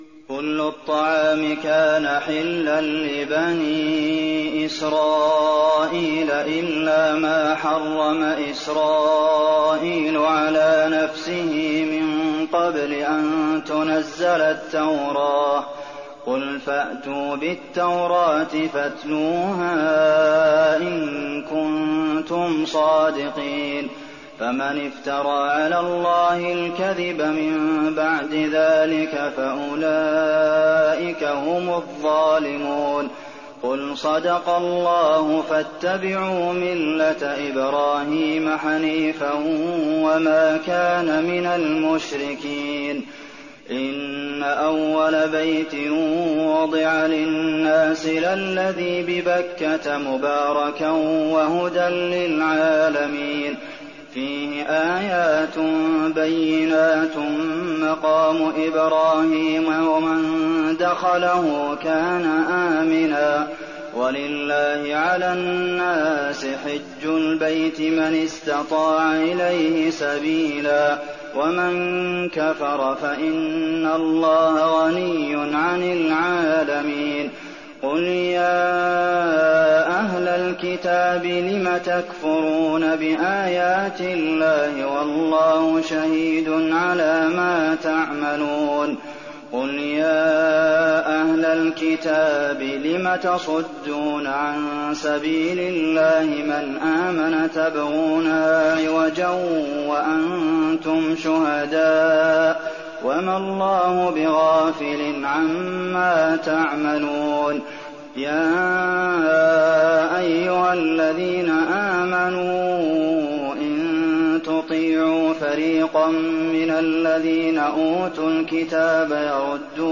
تراويح الليلة الرابعة رمضان 1419هـ من سورة آل عمران (93-168) Taraweeh 4th night Ramadan 1419H from Surah Aal-i-Imraan > تراويح الحرم النبوي عام 1419 🕌 > التراويح - تلاوات الحرمين